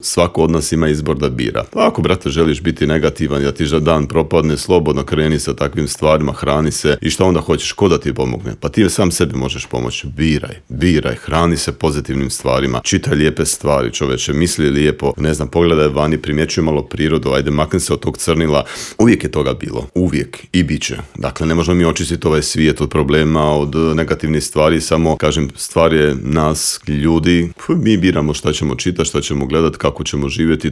Intervju Media servisa